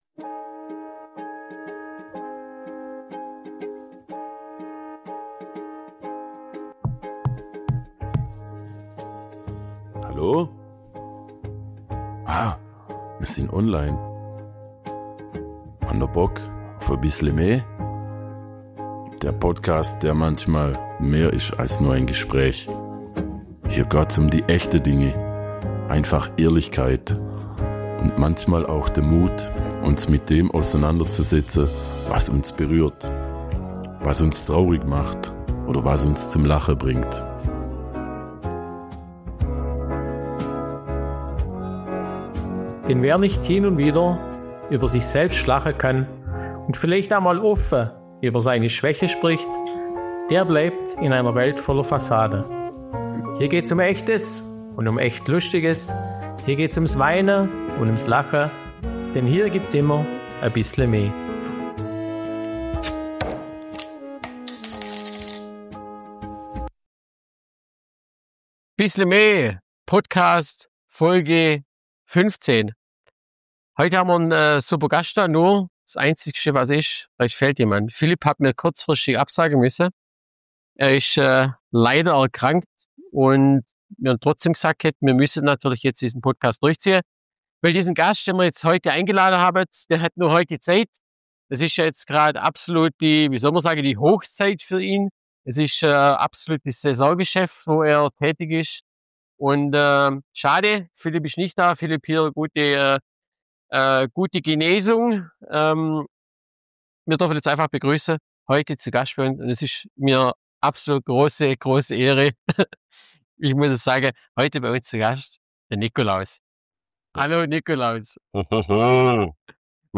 ~ Bissle me – Schwoba-Podcast aus´m Schlofsack Podcast
Zwischen raschelndem Bart, schwitzenden Rentierhandschuhen und der Frage, wie viel Hohoho eigentlich in so einen Stoffbeutel passt, entsteht a Folge voller Weihnachtszauber, schwäbischem Gschwätz und herrlichem Chaos.